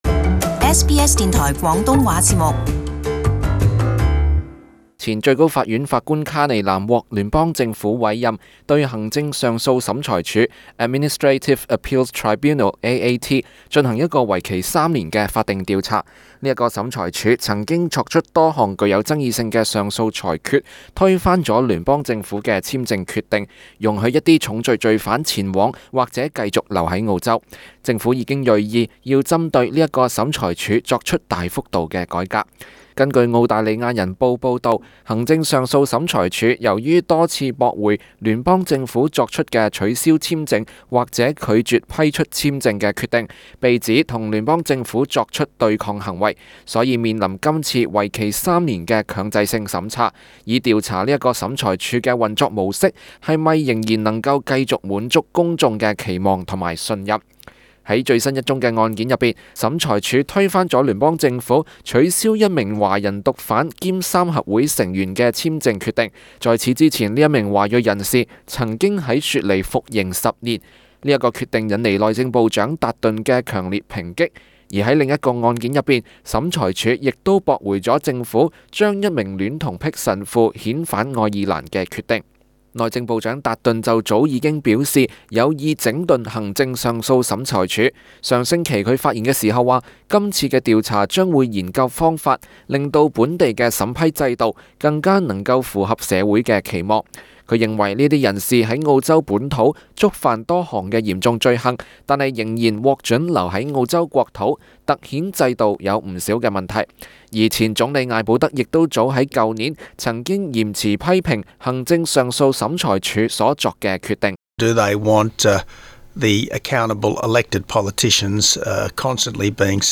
【時事報導】聯邦政府對行政上訴審裁處展開調查